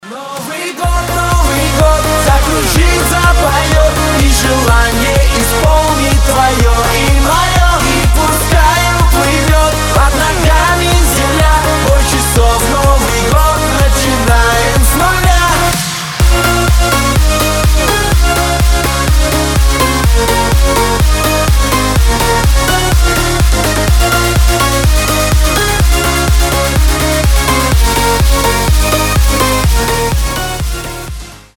позитивные
громкие
энергичные
progressive house